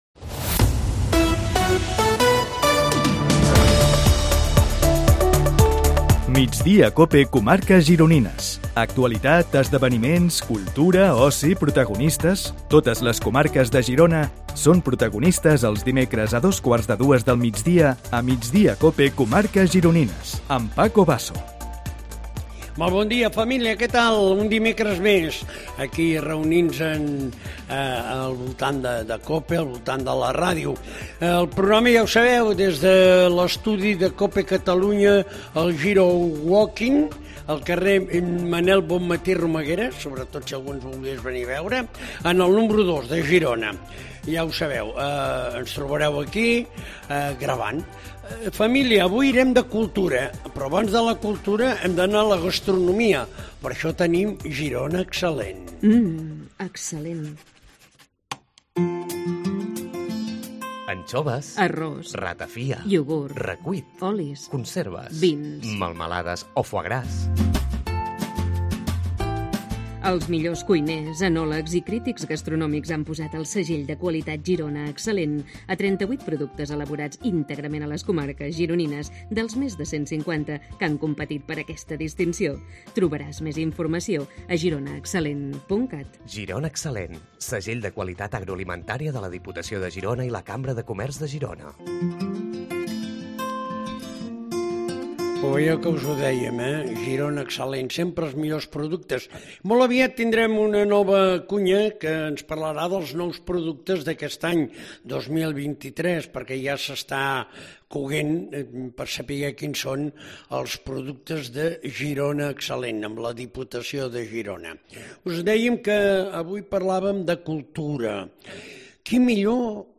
AUDIO: Entrevistem els protagonistes de l'actualitat a les comarques gironines